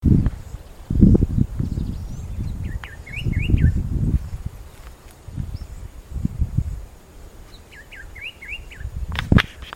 Bluish-grey Saltator (Saltator coerulescens)
Life Stage: Adult
Condition: Wild
Certainty: Observed, Recorded vocal